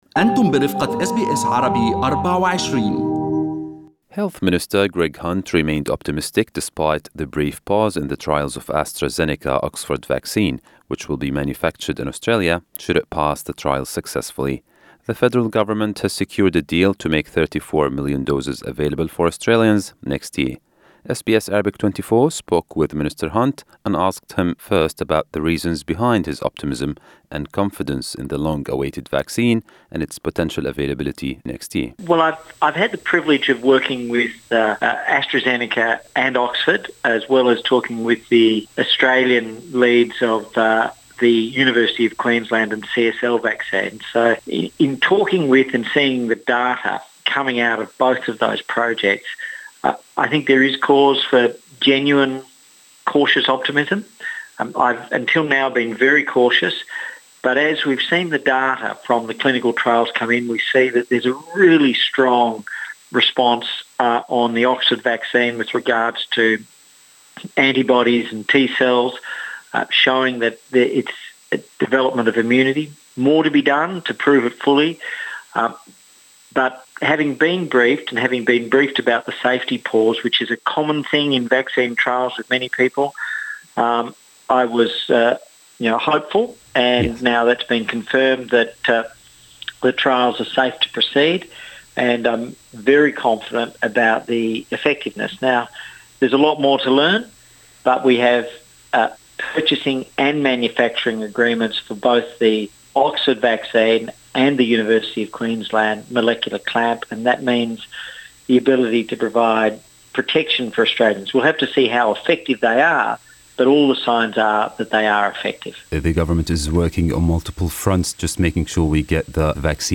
Federal Health Minister Greg Hunt speaks to SBS Arabic24 about the coronavirus vaccine, Australia's domestic and international borders and the country's ability to contain a large outbreak.